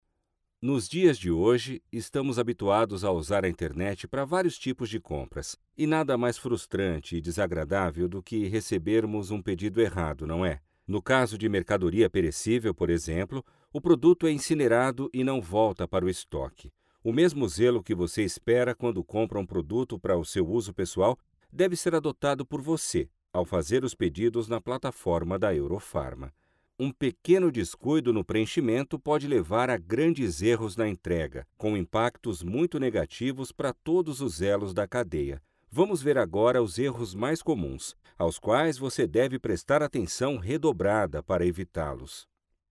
Kommerziell, Natürlich, Zuverlässig, Freundlich, Corporate
Erklärvideo
His voice is gentle, friendly and conveys reliability.